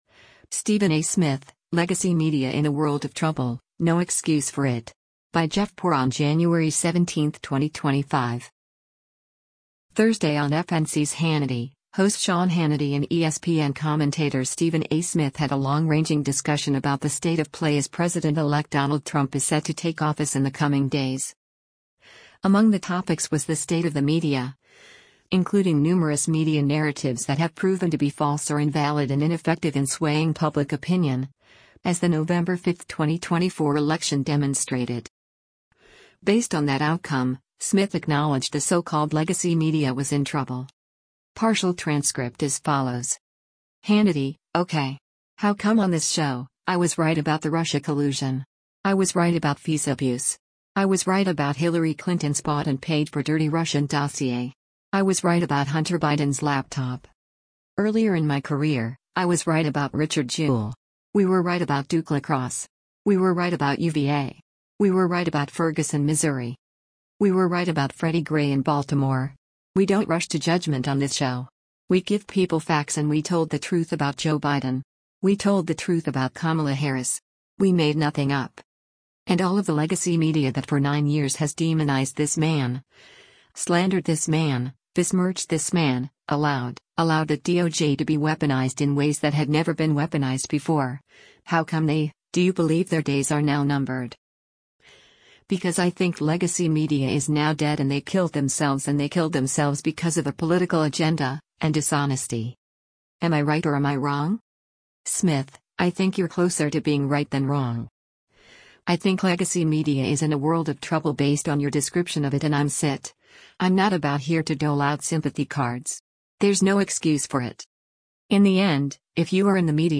Thursday on FNC’s “Hannity,” host Sean Hannity and ESPN commentator Stephen A. Smith had a long-ranging discussion about the state of play as President-elect Donald Trump is set to take office in the coming days.